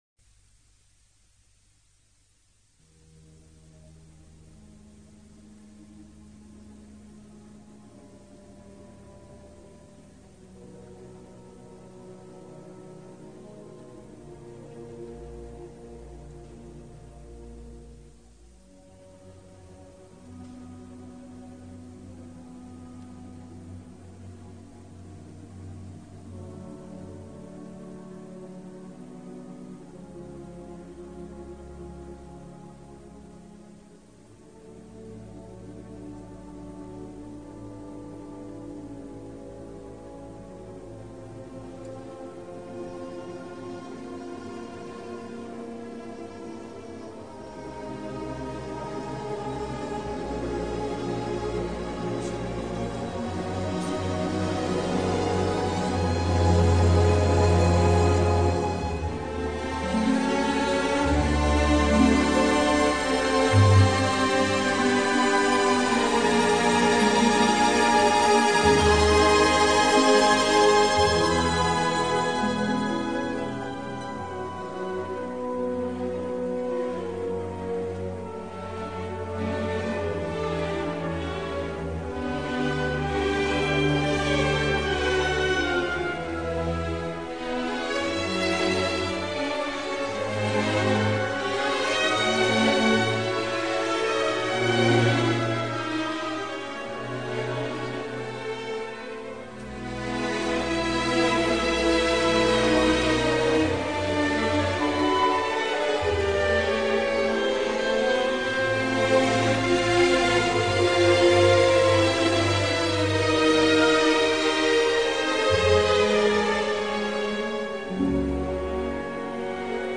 Philharmonia Orchestra.
Une mort d’Iseult hallucinante et hallucinée...